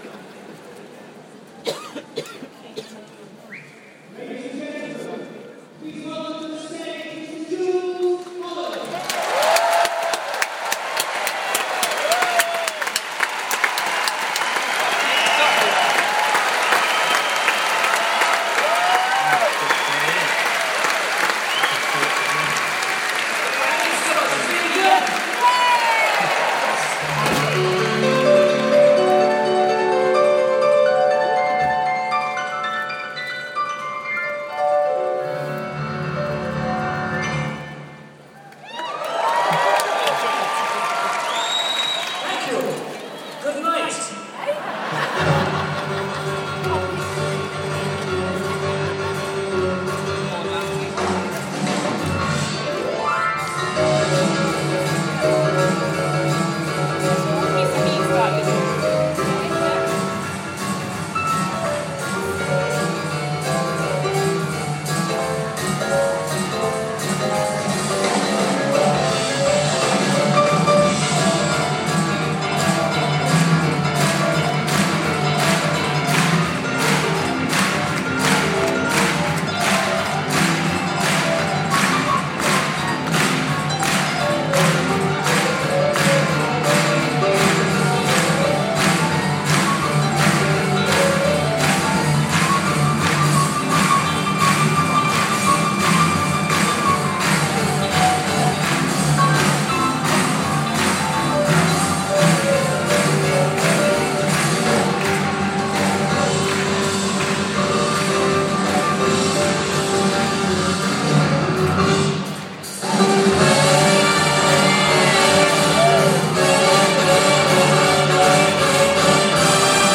Here are a couple of numbers from the start of the evening.